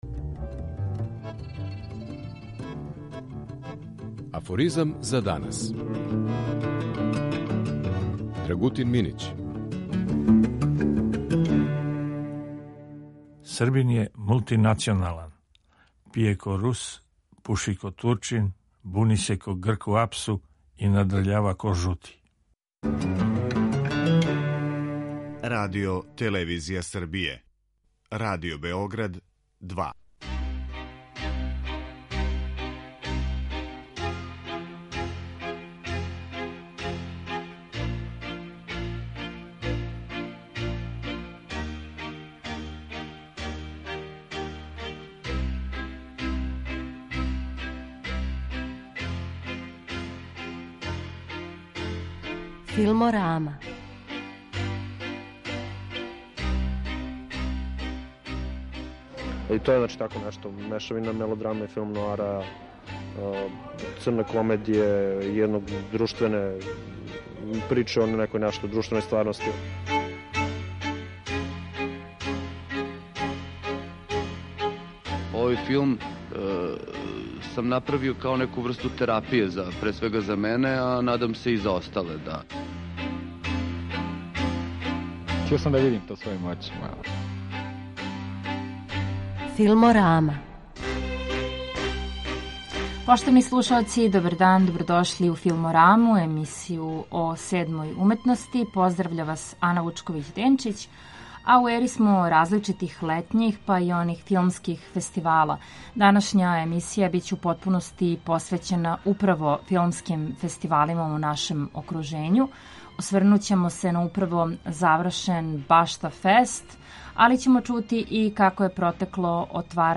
У Бајиној Башти одржан је Башта фест, фестивал који фокус ставља на кратки играни филм. Данашња емисија доноси својеврсну рекапитулацију најважнијих момената фестивала и разговор са његовим гостима